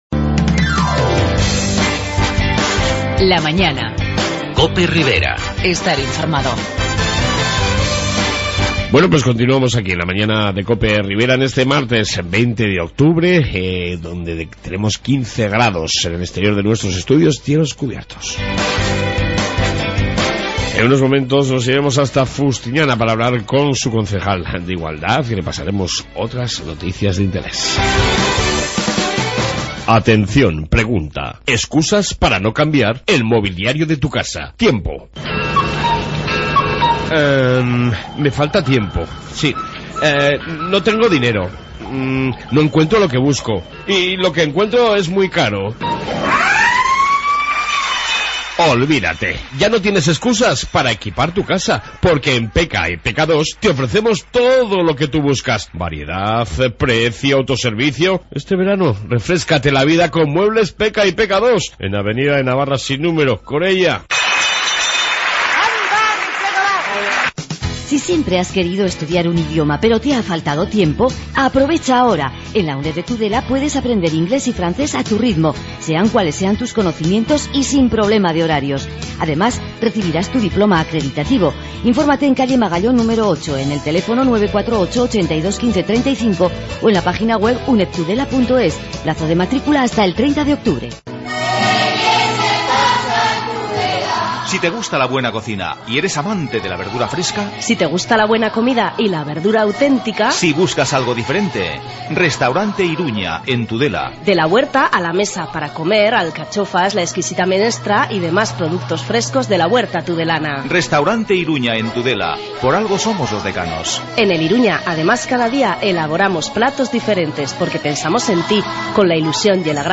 Redacción digital Madrid - Publicado el 20 oct 2015, 20:17 - Actualizado 19 mar 2023, 04:30 1 min lectura Descargar Facebook Twitter Whatsapp Telegram Enviar por email Copiar enlace En esta 2 parte Noticias Riberas y entrevista con la concejal de Igualdad de Fustiñana lola Arrondo sobre el apoyo de Fustiñana junto con Ribaforada a la causa del cancer de mama.